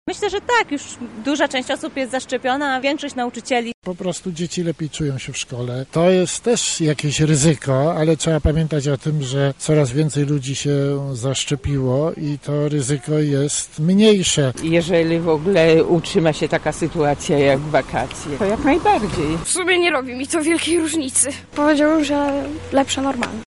[SONDA] Dzieci od września wracają do szkół. Co na ten temat uważają lublinianie?
Z mieszkańcami Lublina rozmawiała nasza reporterka: